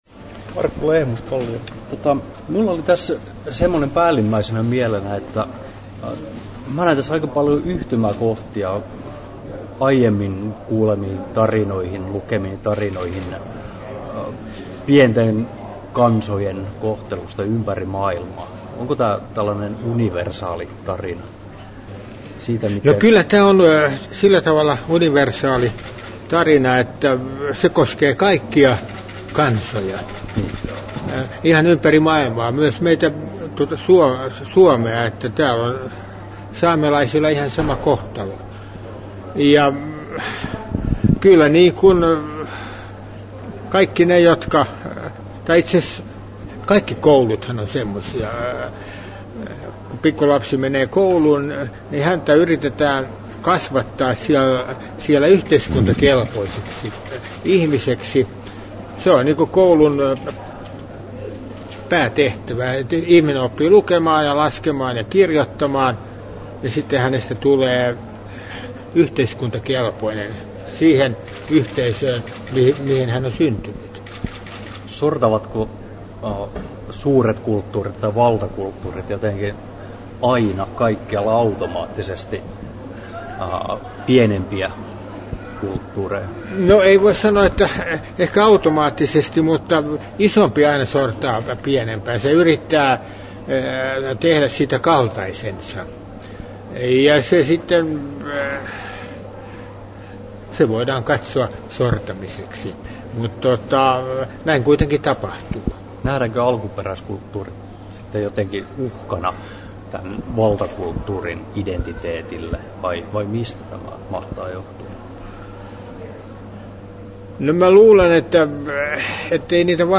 13'10" Tallennettu: 24.2.2010, Turku Toimittaja